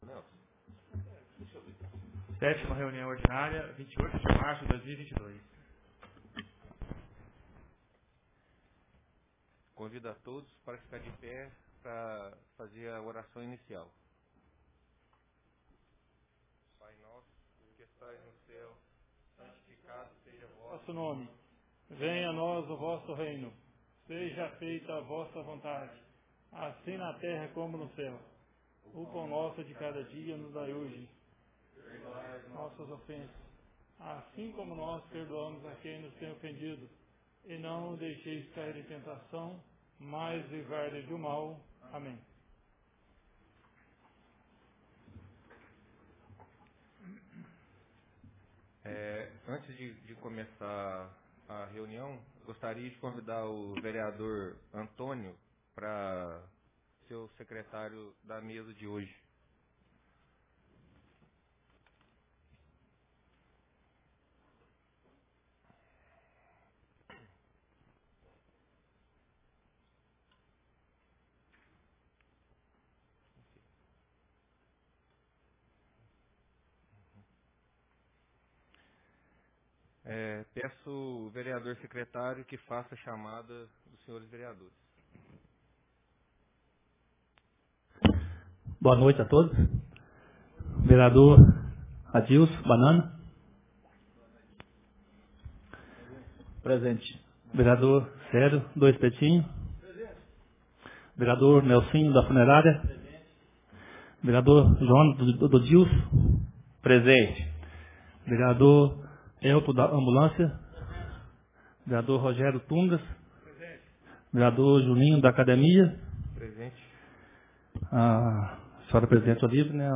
Ata da 7ª Reunião Ordinária de 2022 — Câmara Municipal